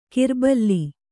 ♪ kirballi